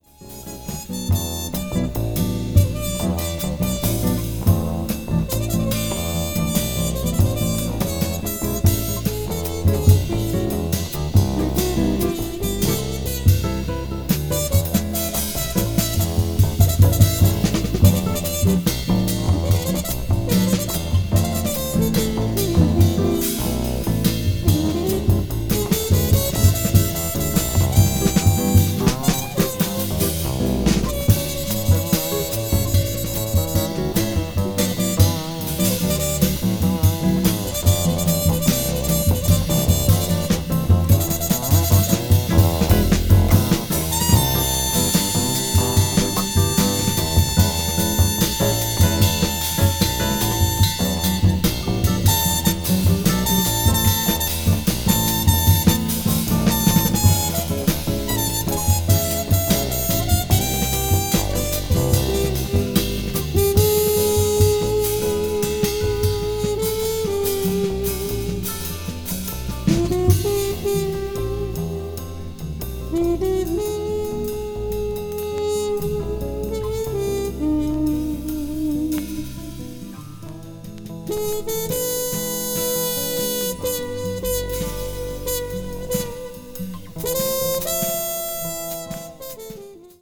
media : EX-/EX-(わずかなチリノイズが入る箇所あり,軽いプチノイズ数回あり)